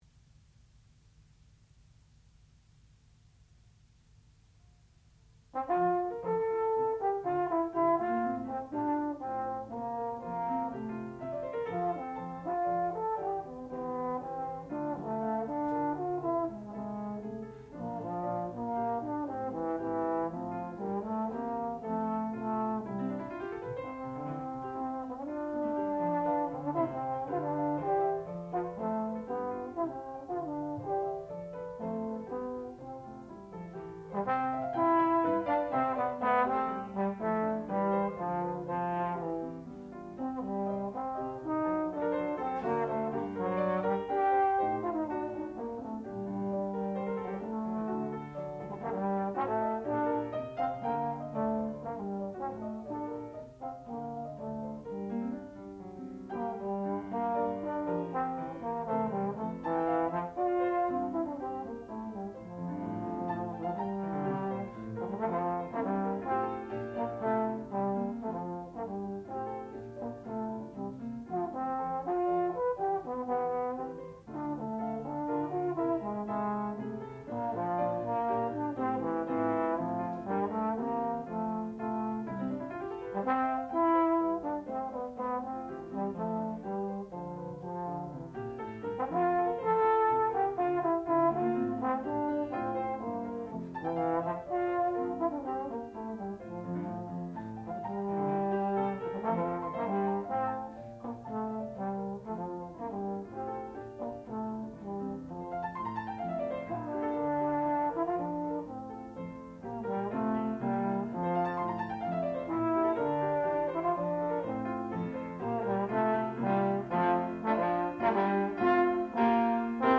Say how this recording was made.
Qualifying Recital